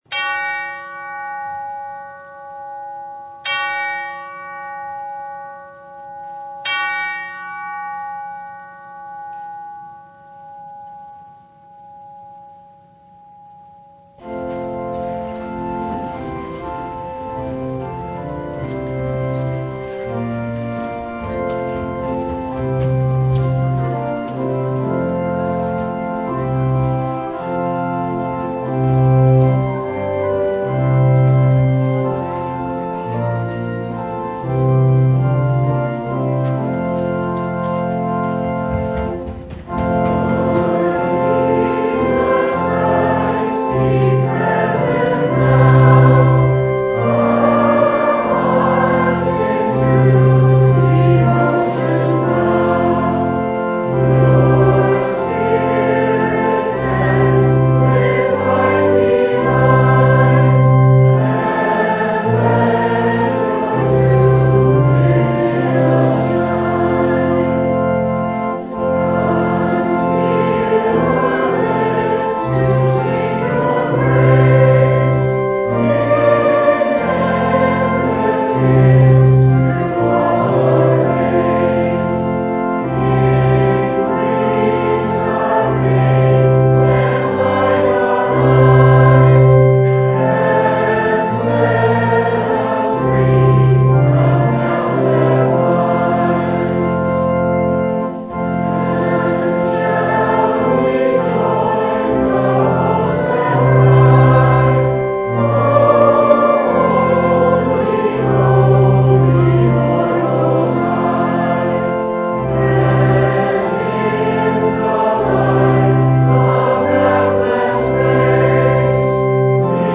Luke 5:1-11 Service Type: Sunday Isaiah was trapped.